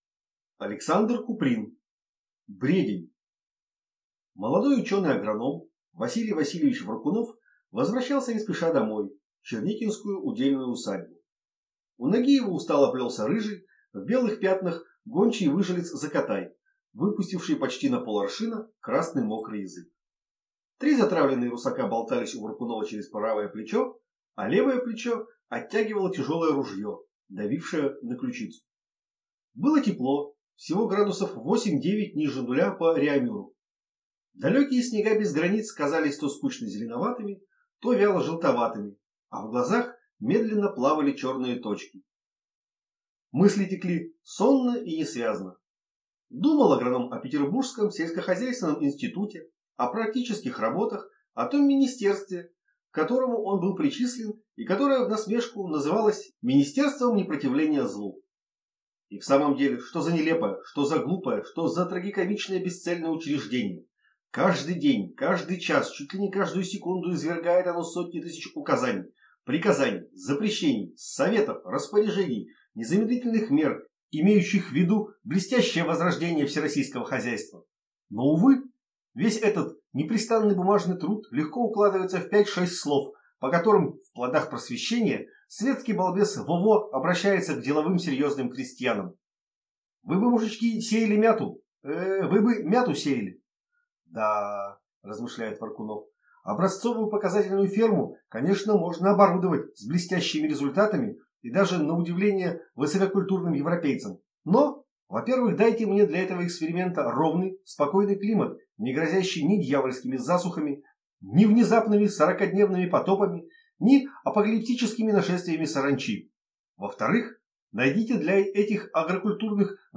Аудиокнига Бредень